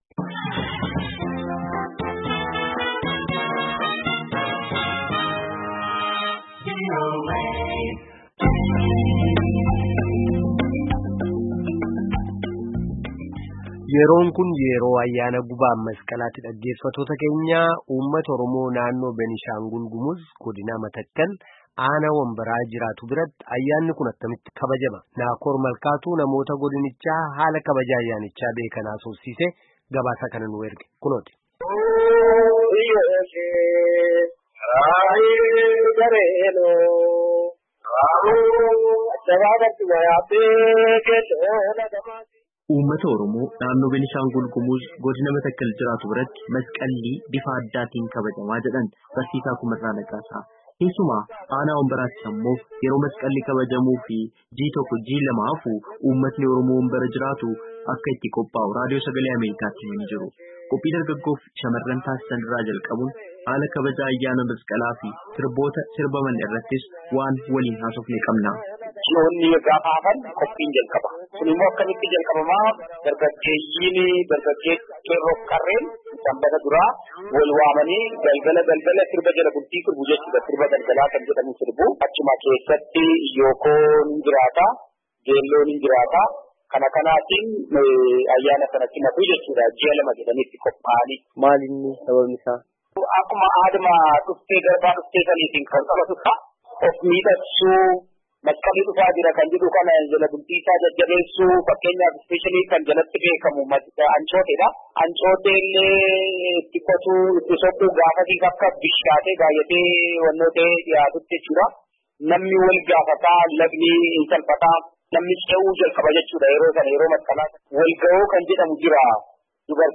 namoota godinichaa haala kabajaa ayyaanichaa beekan haasofsiisee jira.